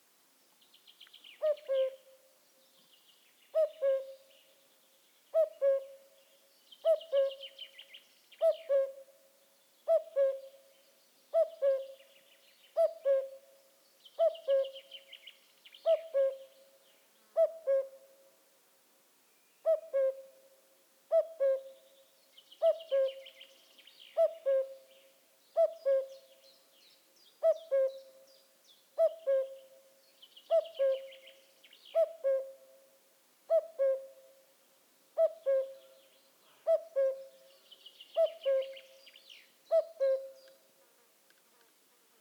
Cuco
Canto
Durante a primavera e o verán, a súa chamada distintiva, un “cu-cu” repetitivo, é un dos sons máis emblemáticos das paisaxes rurais e boscosas de Europa. O canto do macho utilízase tanto para delimitar o seu territorio como para atraer ás femias.